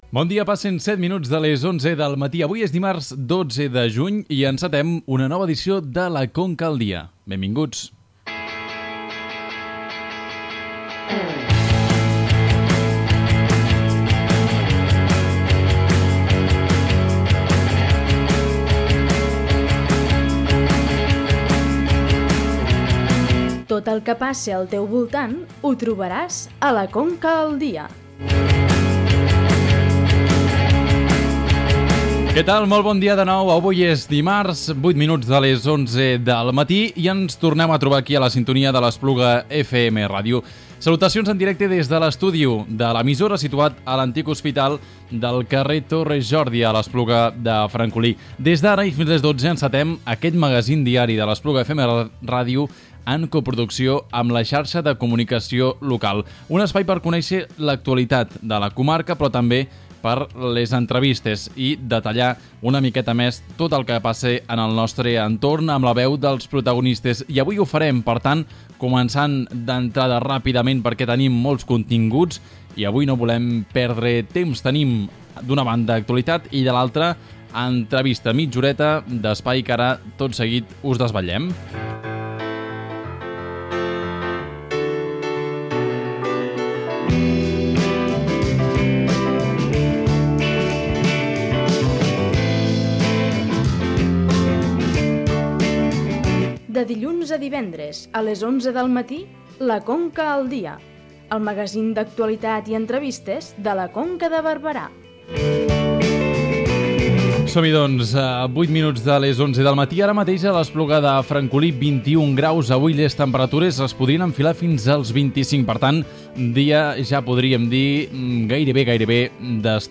En el programa d’avui, hem resumit l’actualitat d’aquest dimarts durant els 20 primers minuts del programa per donar pas a l’entrevista conjunta amb Ràdio Montblanc al president del Consell Comarcal de la Conca, Francesc Benet, al voltant del Pla de Desenvolupament Estratègic de la Conca de Barberà 2018-2025.